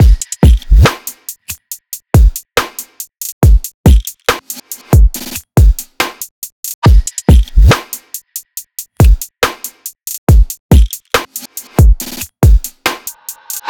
基本的なリズムは出来ましたので、あとは所々に効果音を散りばめています。
ベルのような音やライターをつける音、あとは金属系の効果音です。
あとはビートの最後にリムショットを逆再生したものを置いています。
drum-all.wav